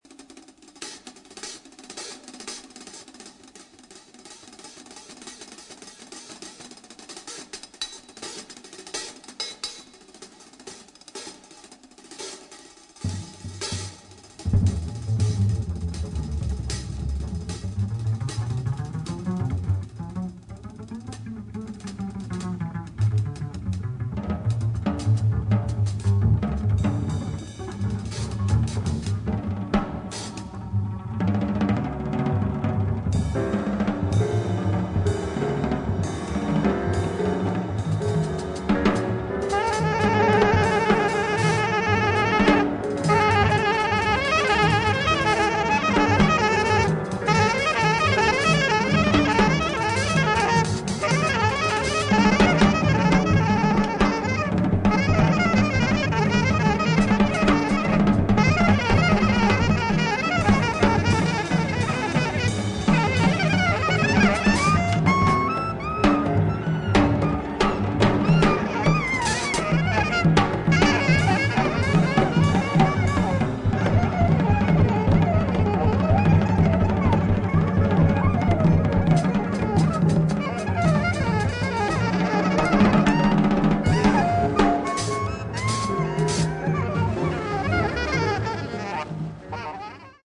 混沌とした無調和のフリーキーなフリーインプロビゼーション。激しいです。。
スリリングなドラムから始まり
パンクな1曲！
渋いベースソロを挟んだ終盤の演奏が宇宙への旅立ちを感じます。